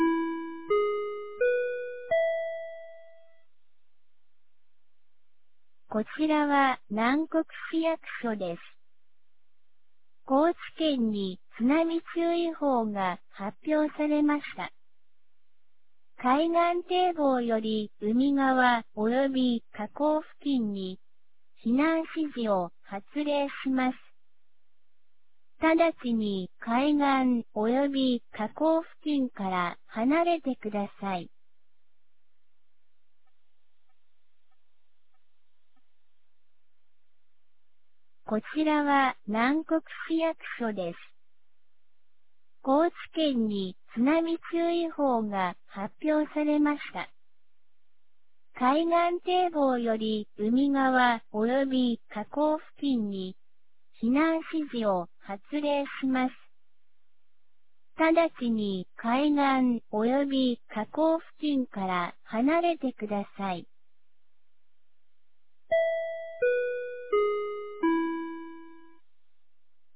放送音声
2024年08月08日 16時48分に、南国市より放送がありました。